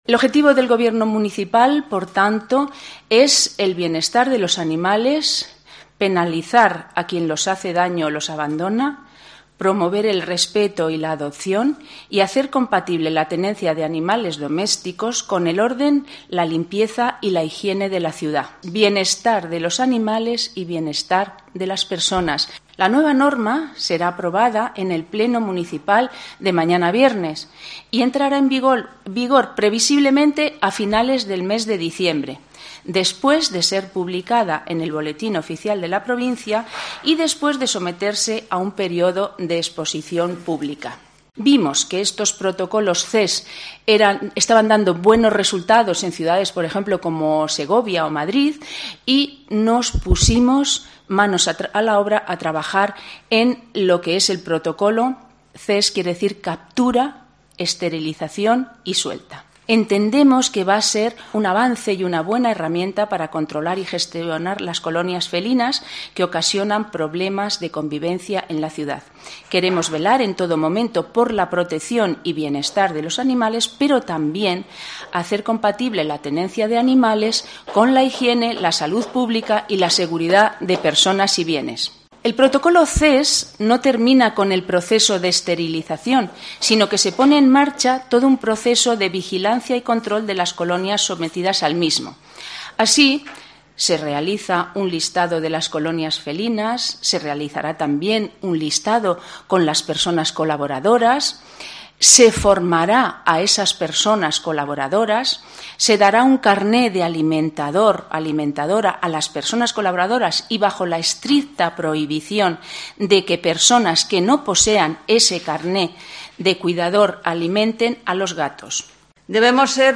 Pilar Sánchez (concejala de Seguridad del Ayuntamiento de Guadalajara): "No podemos tener una ciudad repleta de excrementos, eso se acabó"